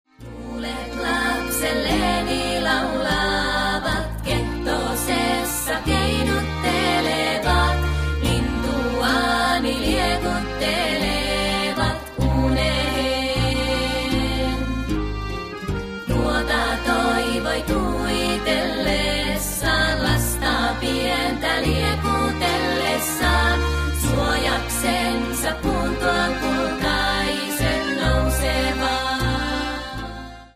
A wonderful slow song